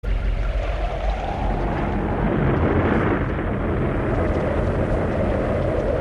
• wind whistling sound effect.ogg
[wind-whistling-sound-effect]__b0z.wav